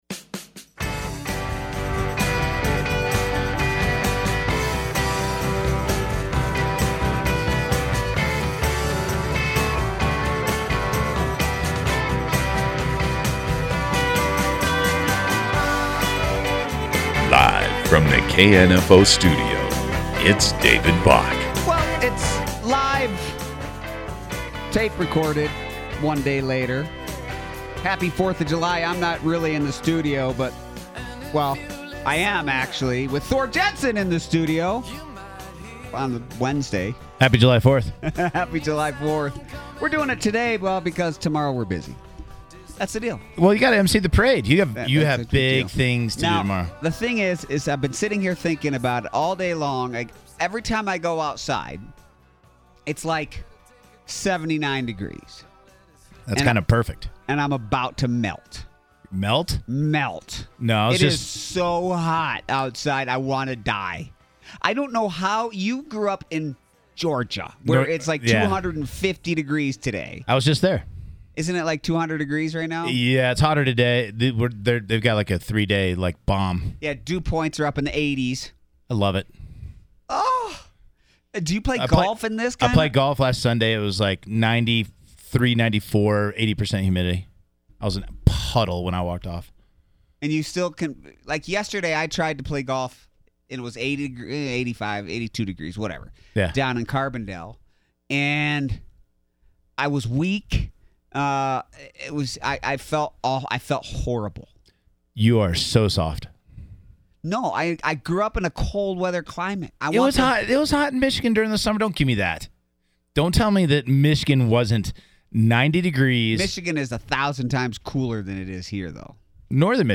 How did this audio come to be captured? This Podcast was from our radio show set for July 4 2019.